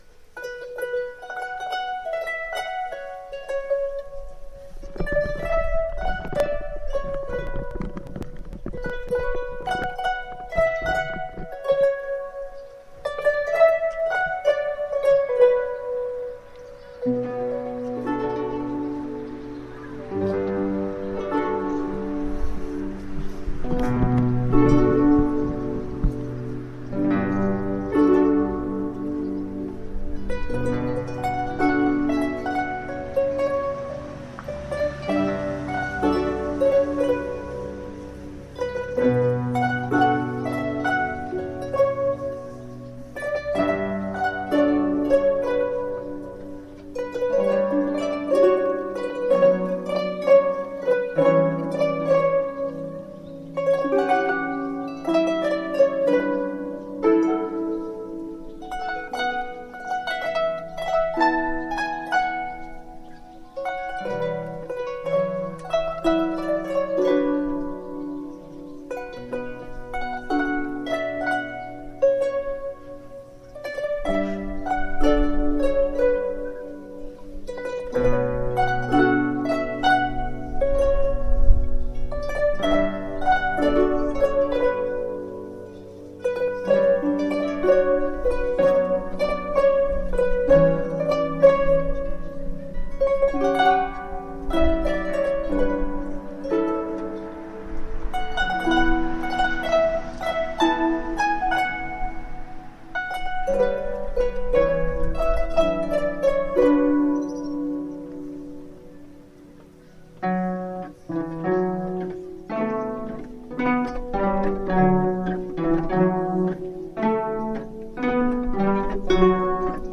03_melodie_tour_harpes.mp3